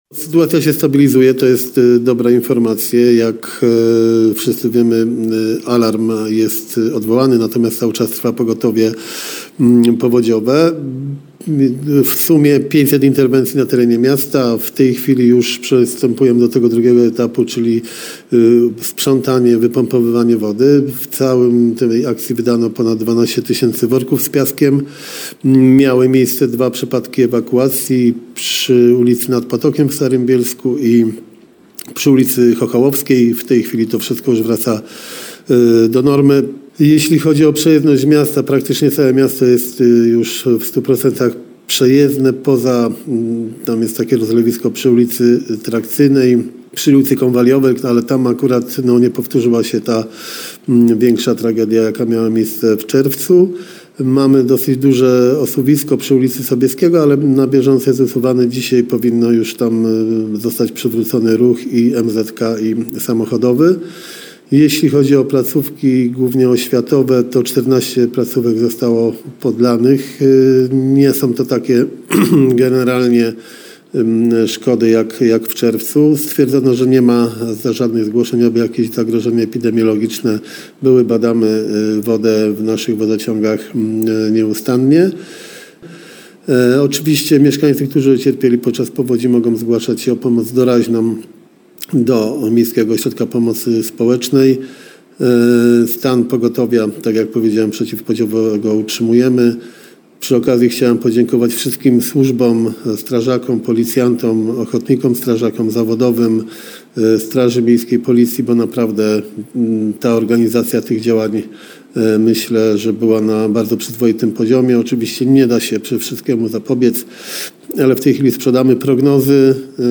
Wypowiedź Jarosława Klimaszewskiego: